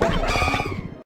Cri de Vert-de-Fer dans Pokémon Écarlate et Violet.